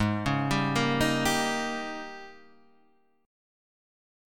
AbM7sus2sus4 chord